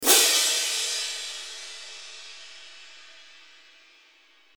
Figur 46 (ekstern lyd) viser ni forskjellige skisser til lyden av en symbal.
Figur 46: Ni forskjellige skisser (øverst) av en impulsiv symballyd (spektrogram nederst).